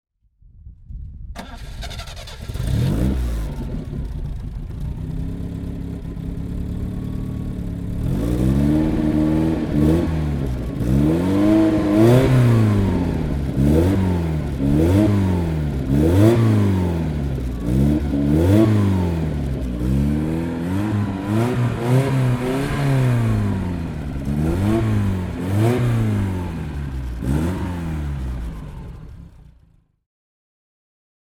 Lotus Esprit S1 (1978) - Starten und Leerlauf
Lotus_Esprit_S1_1978.mp3